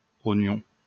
来自 Lingua Libre 项目的发音音频文件。 语言 InfoField 法语 拼写 InfoField ognon 日期 2018年7月18日 来源 自己的作品